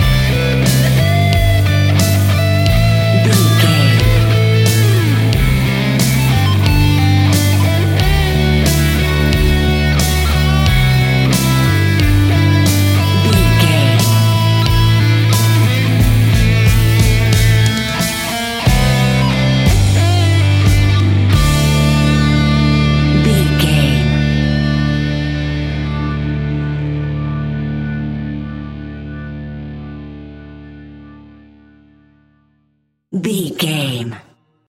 Epic / Action
Fast paced
Mixolydian
hard rock
blues rock
Rock Bass
heavy drums
distorted guitars
hammond organ